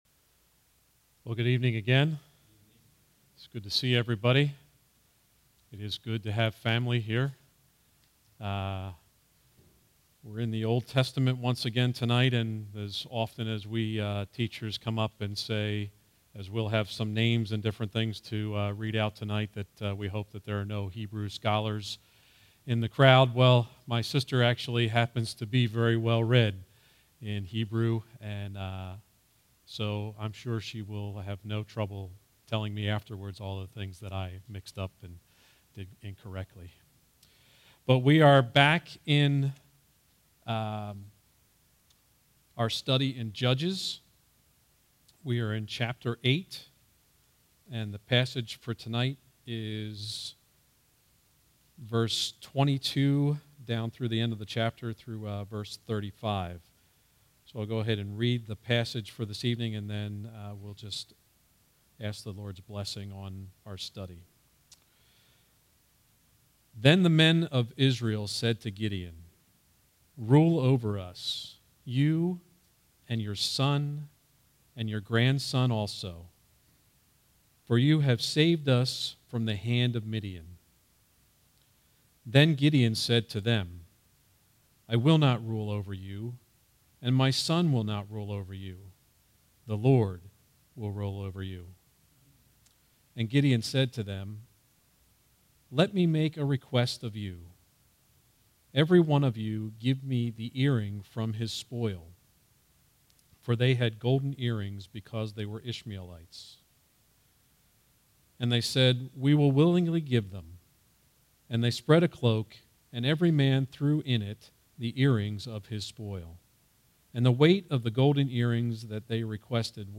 All Sermons Judges 8:22-35